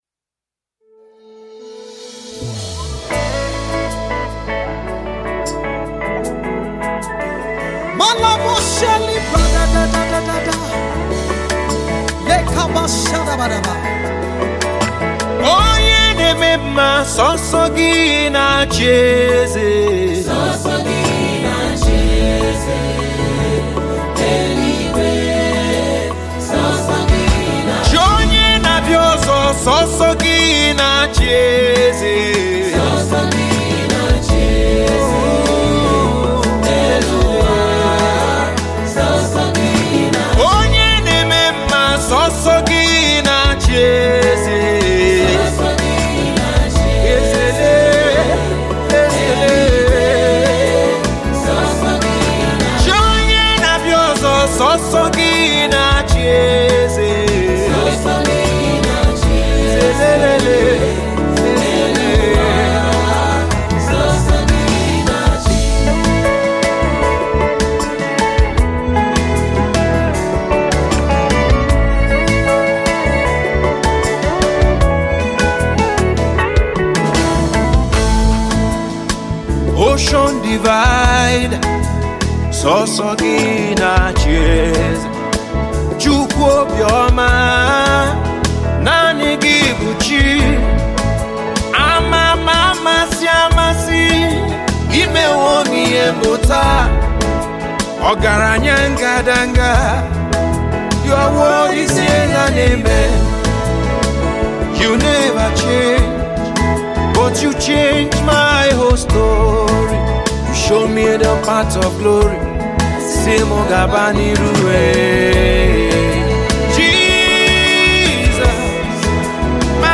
Gospel music
inspiring worship song
unique African touch